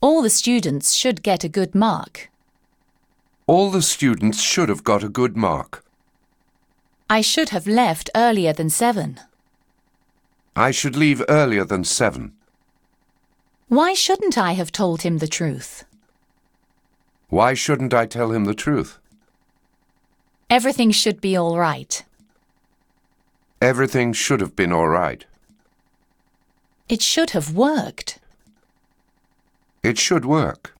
Fiche 13, exercice supplémentaire - Consigne Écoutez le dialogue et justifiez l’emploi de since .